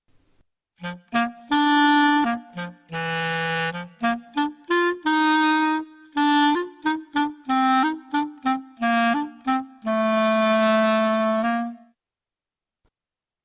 clarinete.mp3